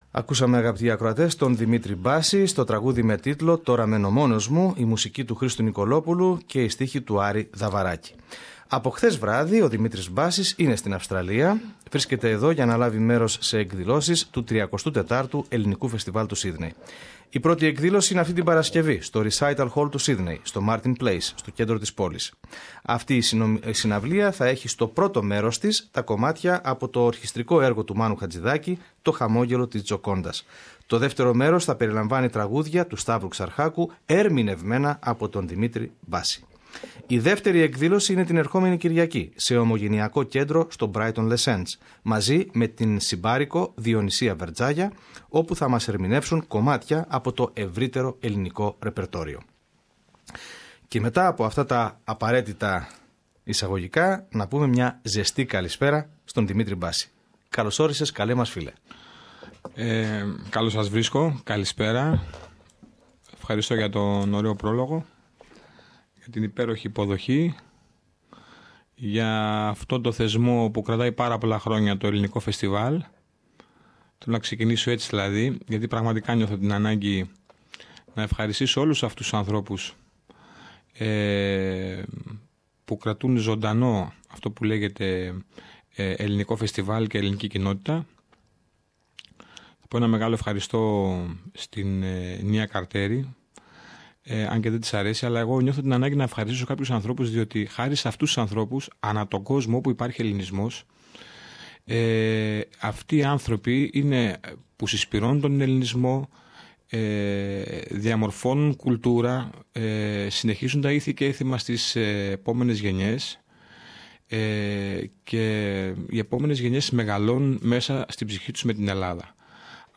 Ο δημοφιλής Έλληνας τραγουδιστής Δημήτρης Μπάσης, μιλά